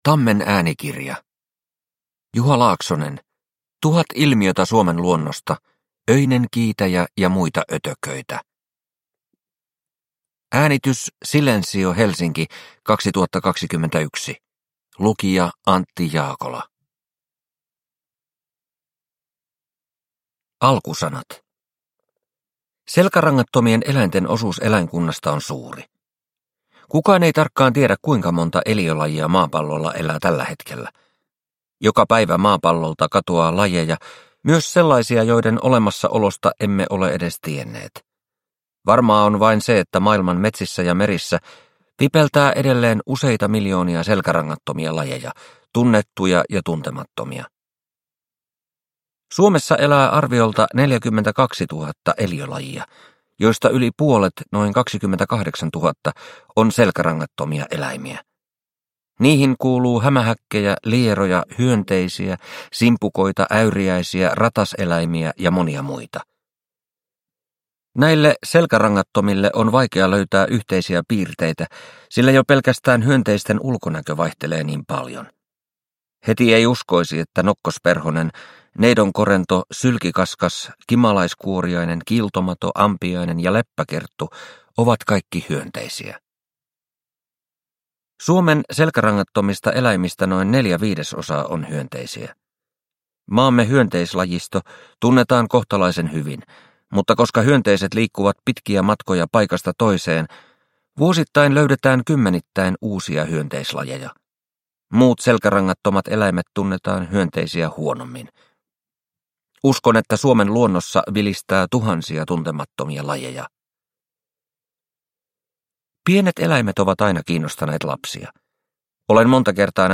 Öinen kiitäjä ja muita ötököitä – Ljudbok – Laddas ner